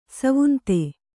♪ savunte